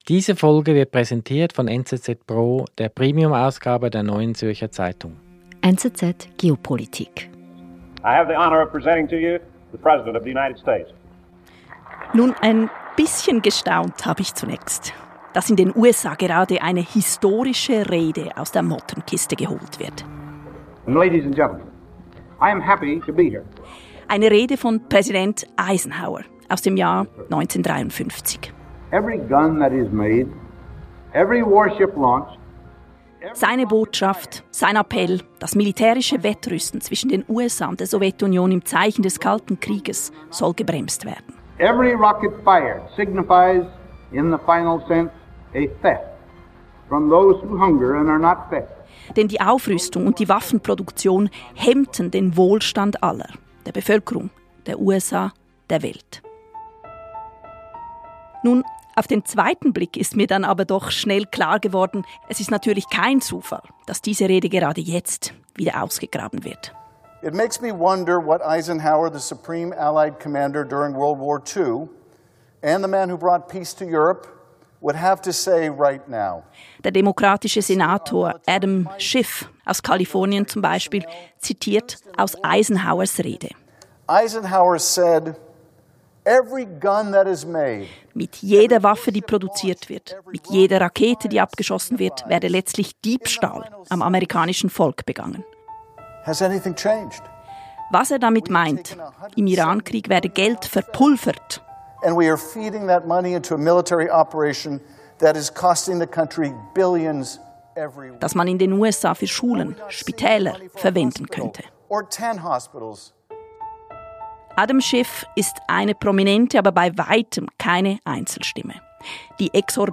Der Ökonom und Konfliktforscher